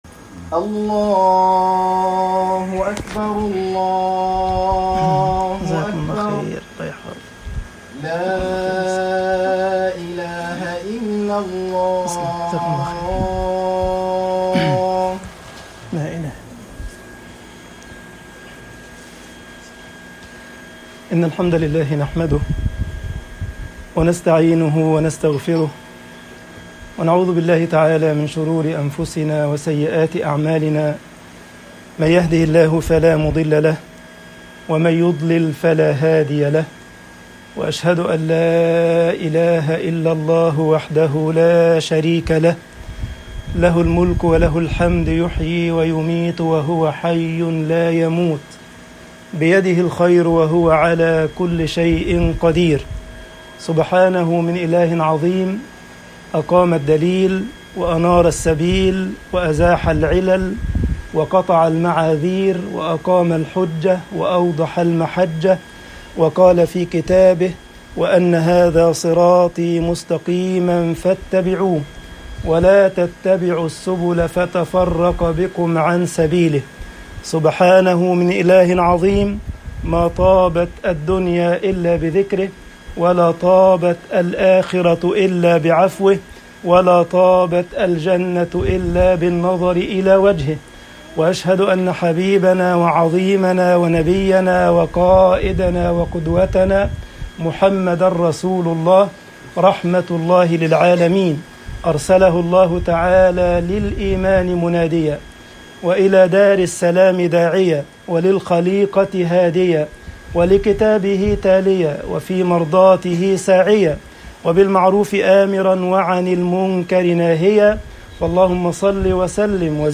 خطب الجمعة - مصر احذَروا لصُوصَ الْقُلُوب طباعة البريد الإلكتروني التفاصيل كتب بواسطة